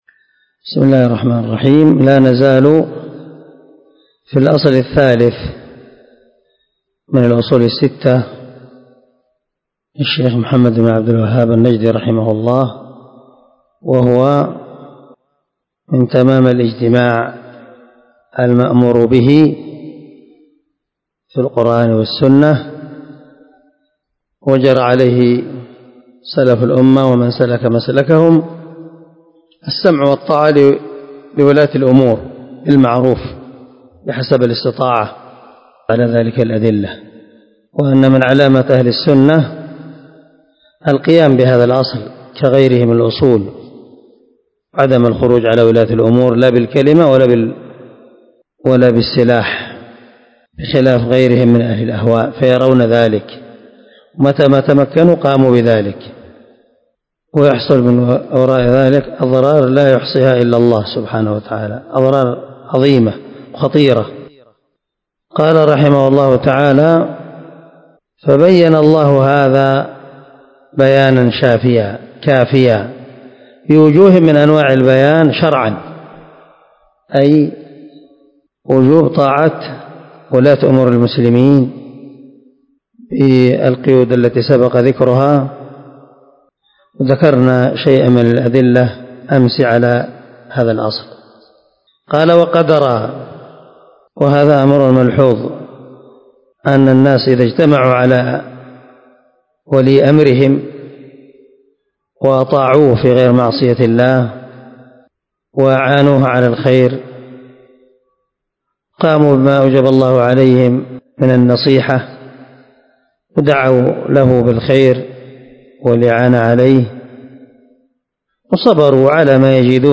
🔊 الدرس 5 من شرح الأصول الستة (تابع الأصل الثالث)